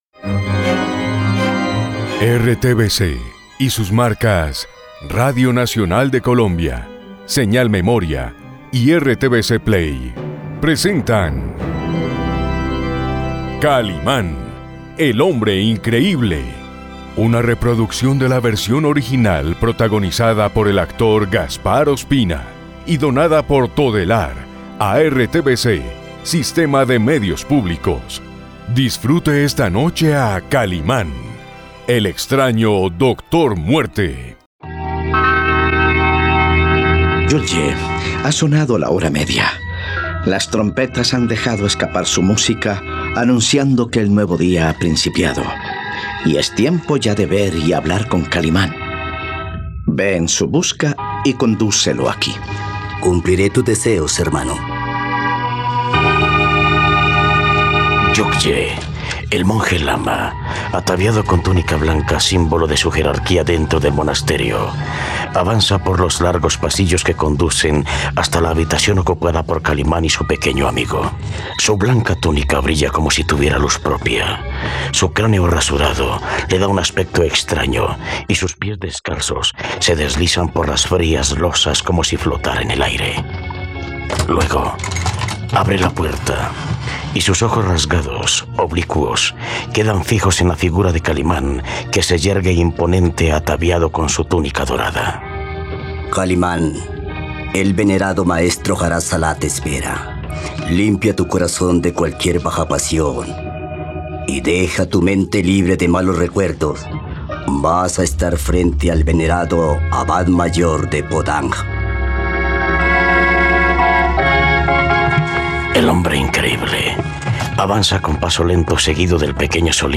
..Con el sonido de las trompetas que anuncian el amanecer, el Lama se encuentra con Kalimán y Solín.
radionovela